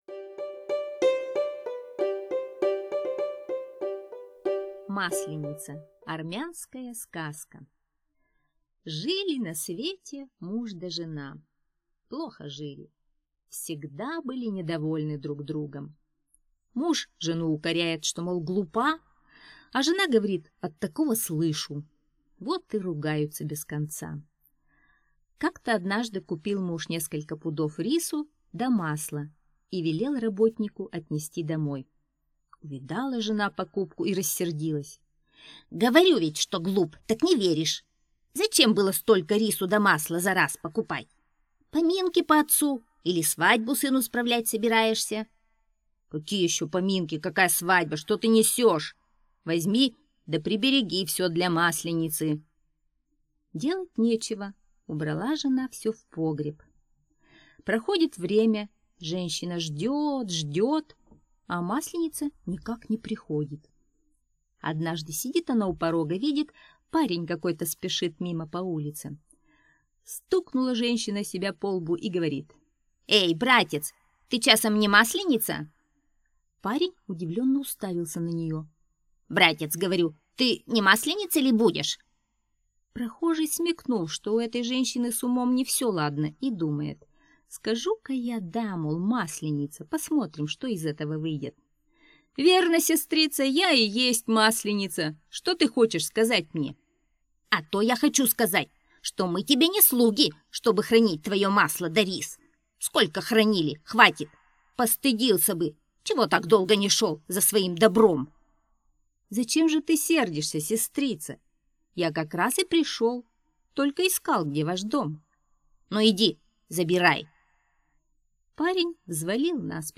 Масленица - армянская аудиосказка - слушать онлайн